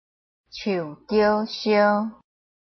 臺灣客語拼音學習網-客語聽讀拼-詔安腔-開尾韻
拼音查詢：【詔安腔】sio ~請點選不同聲調拼音聽聽看!(例字漢字部分屬參考性質)